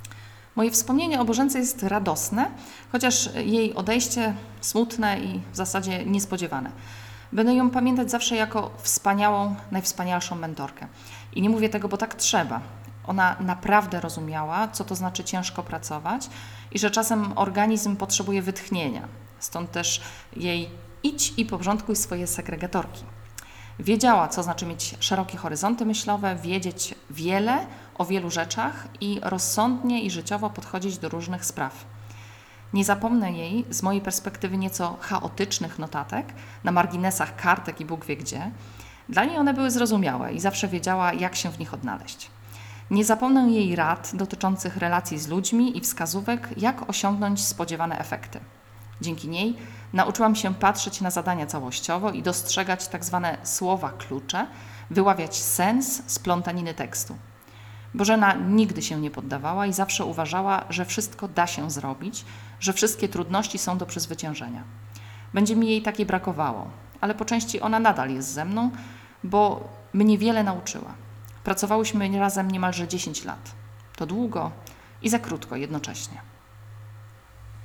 Historia mówiona